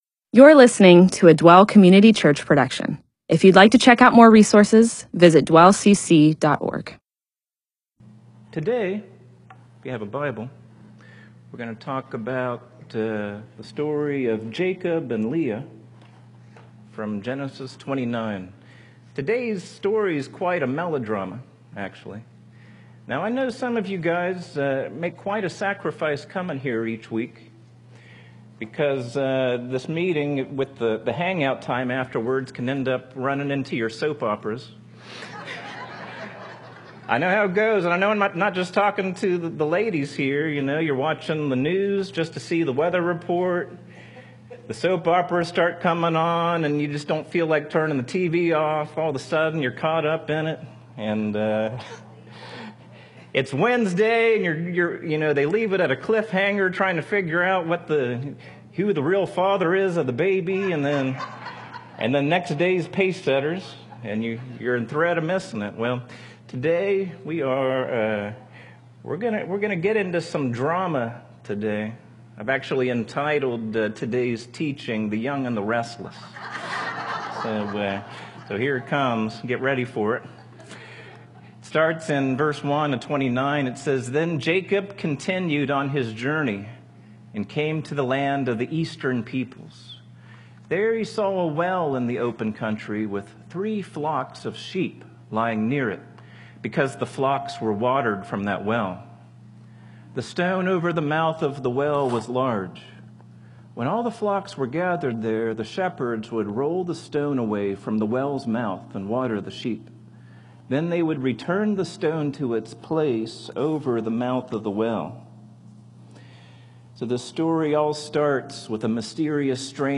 MP4/M4A audio recording of a Bible teaching/sermon/presentation about Genesis 29.